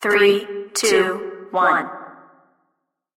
3, 2, 1 (нежным женским голосом)